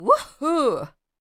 Woohoo! Female Voice Over | Vocal sound for character
woohoo.ogg